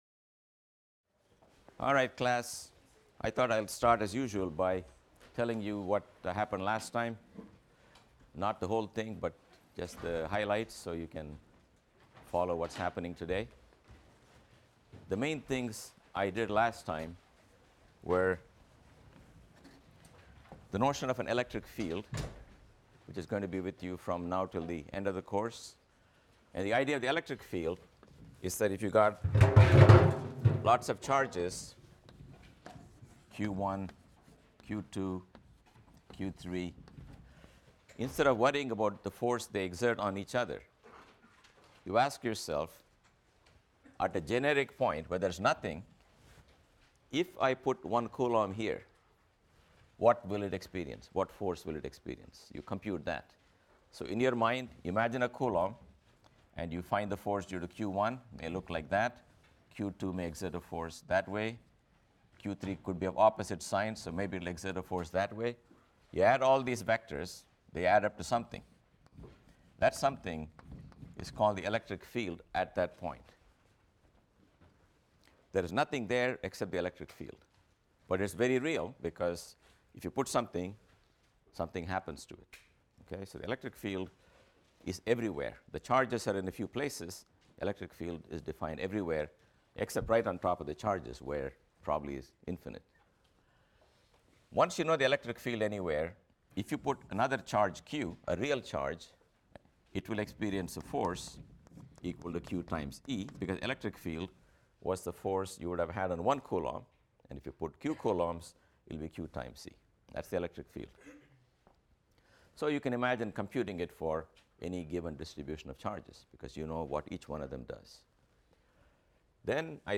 PHYS 201 - Lecture 3 - Gauss’s Law I | Open Yale Courses